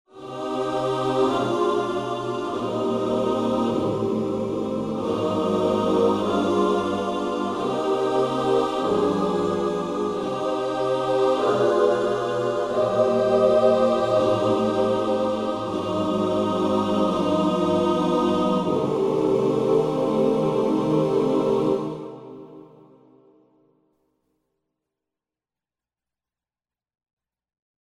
Gospel Oos f demo =1-C02.mp3